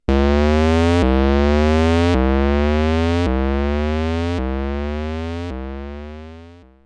不思議系効果音です。
ぼぇ〜ぼぇ〜・・・